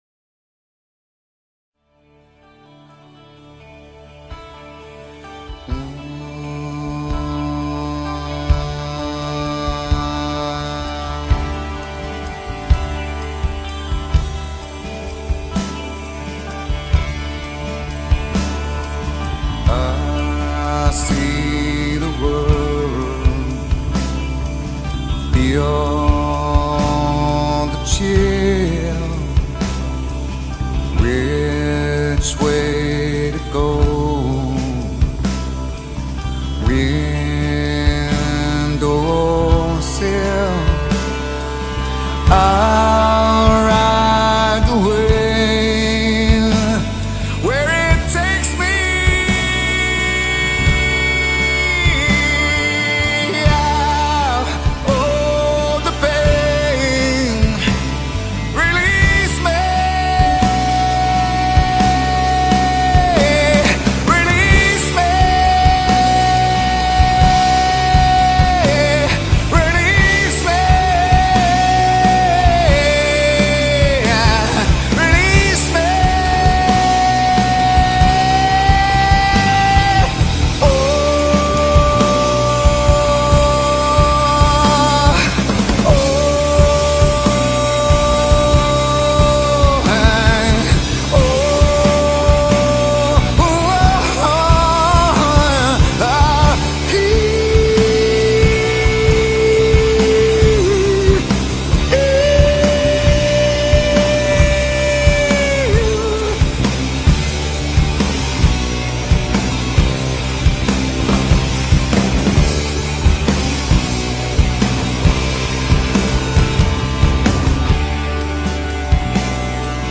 Grunge, Alternative Rock, Hard Rock